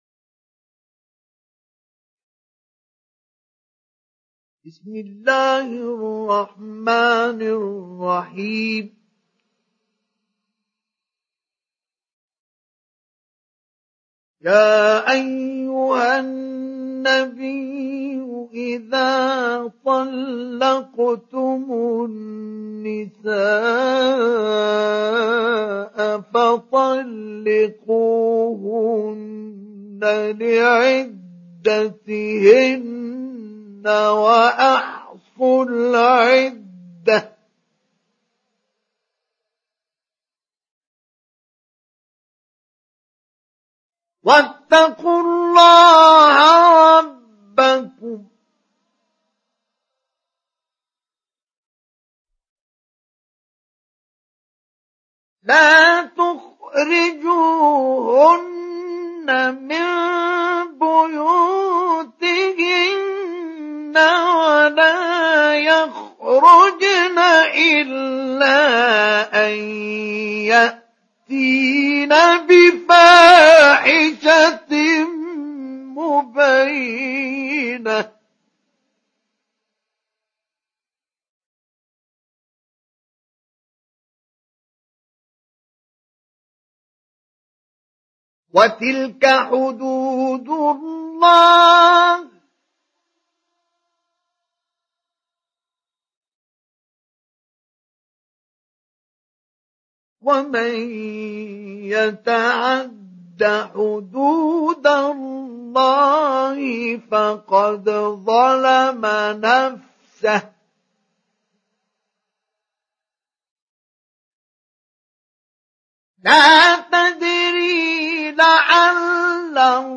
Surat At Talaq Download mp3 Mustafa Ismail Mujawwad Riwayat Hafs dari Asim, Download Quran dan mendengarkan mp3 tautan langsung penuh
Download Surat At Talaq Mustafa Ismail Mujawwad